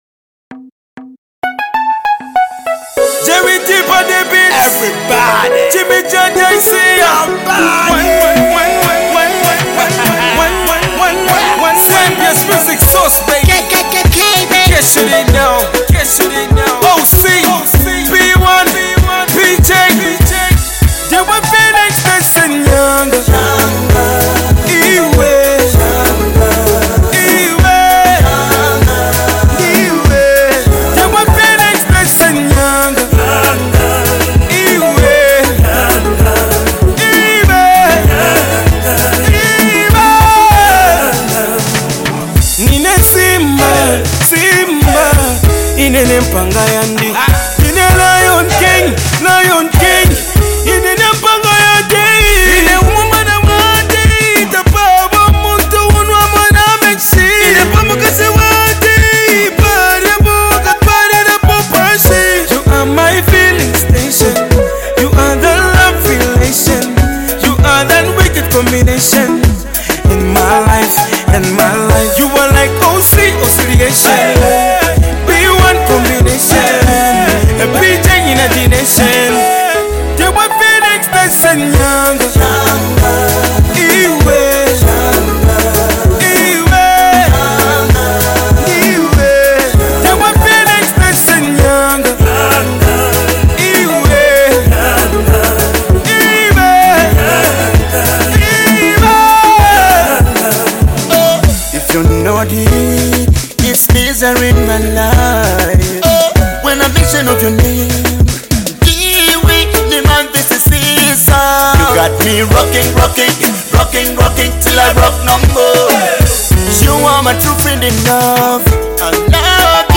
upbeat rhythms